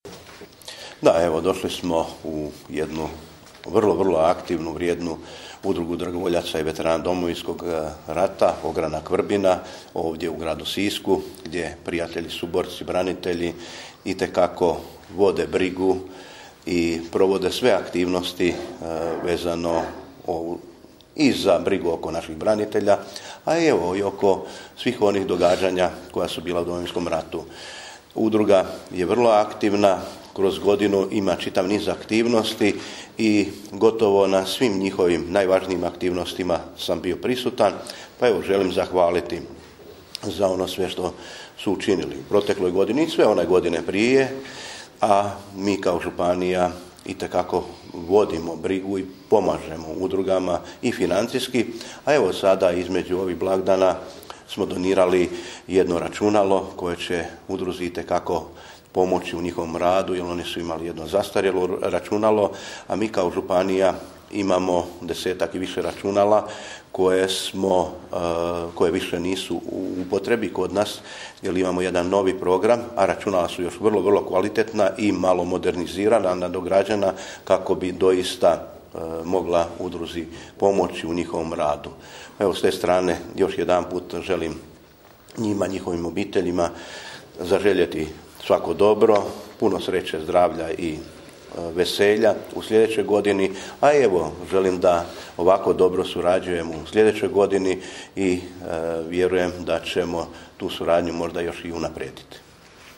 Izjavu koju je u ovoj prigodi dao župan Ivo Žinić možete poslušati ovdje: